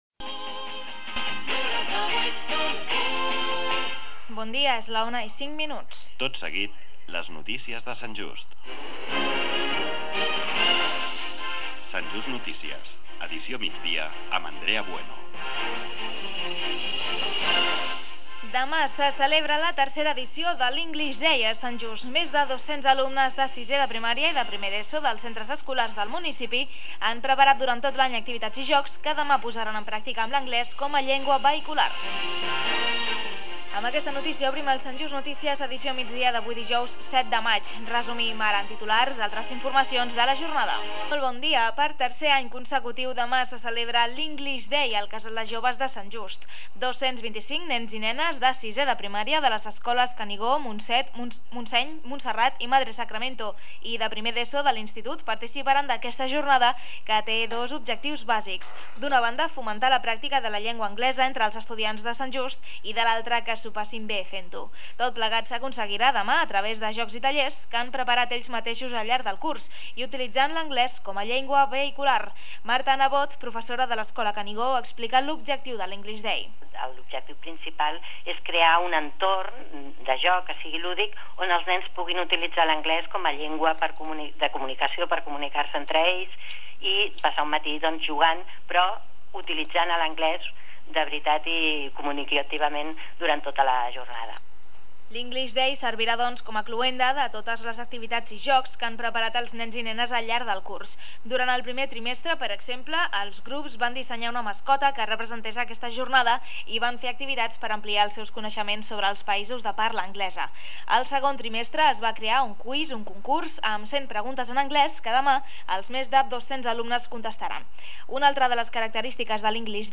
Radiodesvern fa difussió de la tercera edició del English Day La notícia La entrevista English Day 2009 de Sant Just Amb la participació de 225 nens i nenes de 6è i 1r d'ESO.